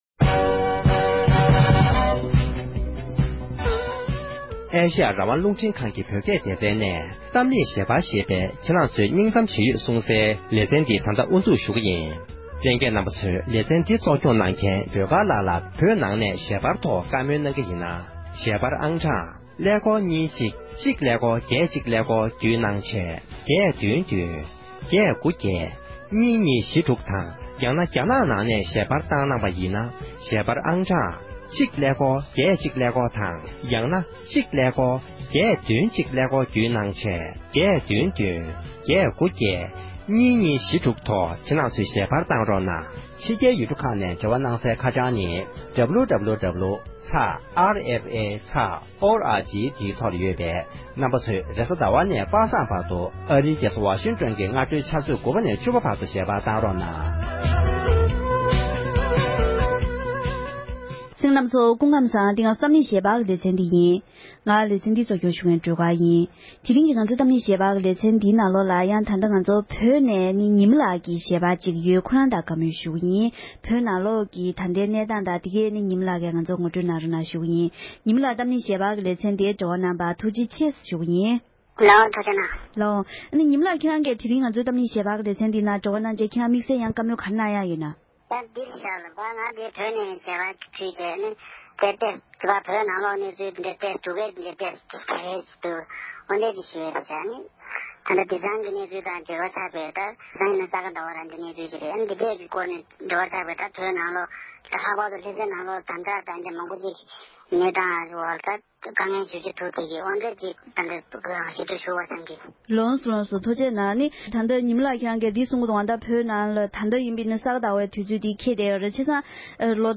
༄༅༎དེ་རིང་གི་གཏམ་གླེང་ཞལ་པར་གྱི་ལེ་ཚན་གྱི་ནང་དུ་བོད་ཀྱི་རྒྱལ་ས་ལྷ་ས་ནས་ཞལ་པར་གནང་མཁན་བོད་མི་ཞིག་གིས་བོད་ནང་གི་ད་ལྟའི་གནས་སྟངས་ངོ་སྤྲོད་གནང་བ་གསན་རོགས༎